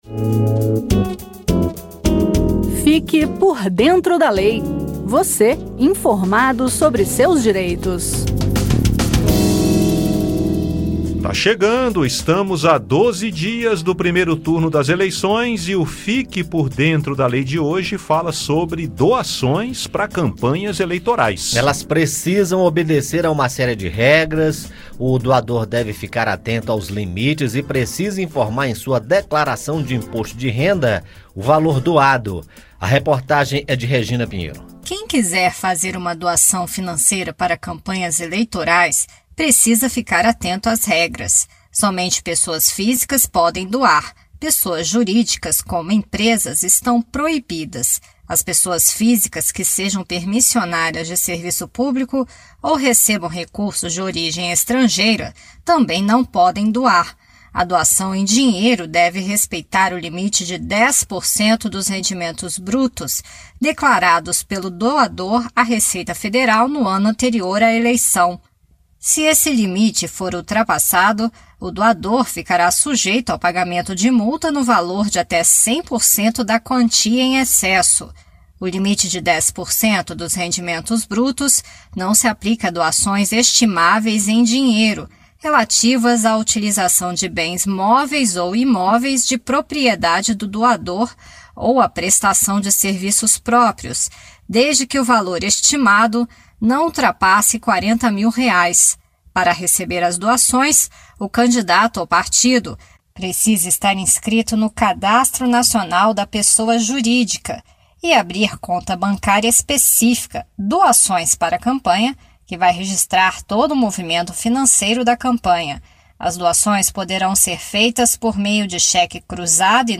Na reportagem